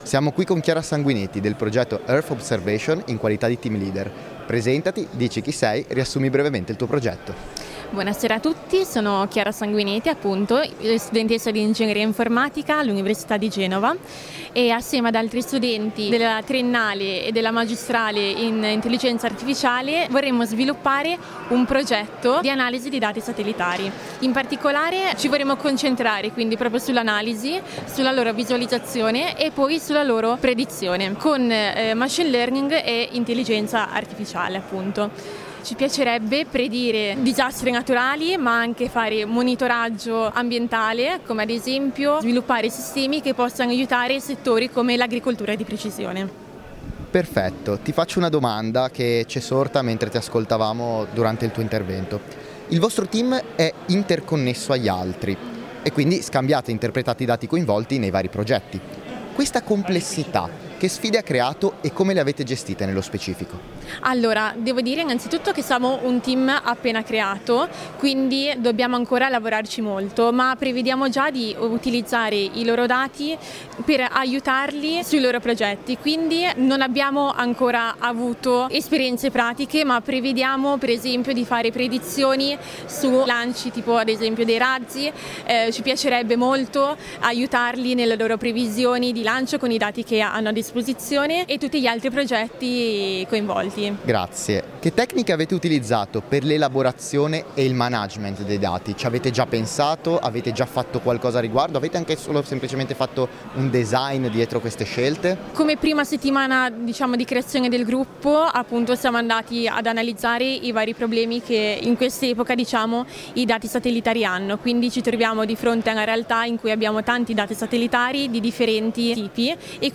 Intervista di: